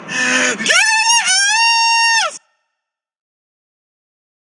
Get out of my house (High Pitch)
get-out-of-my-house-high-pitch.mp3